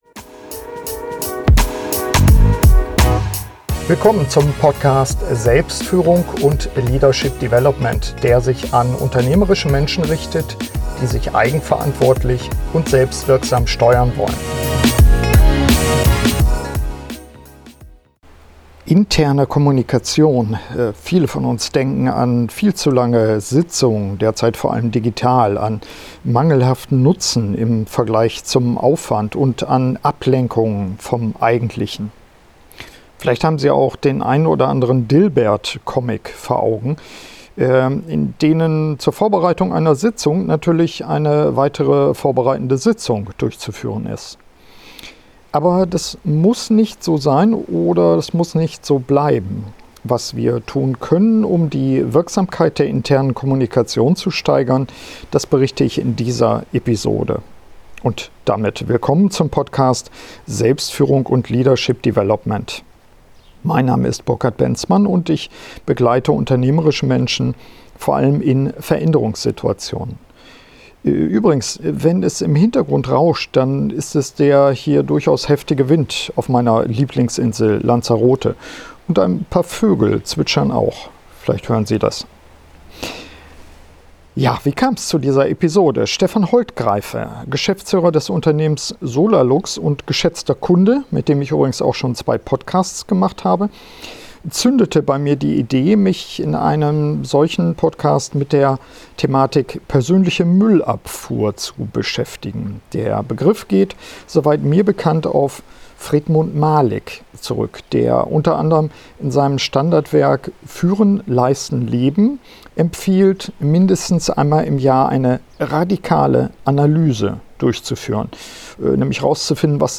In dieser Podcast-Soloepisode lege ich den Fokus auf die interne Kommunikation in Unternehmen und liefere Ihnen fünf konkrete Tipps, wie Sie aufräumen und eine persönliche wie auch gemeinsame Müllabfuhr durchführen können.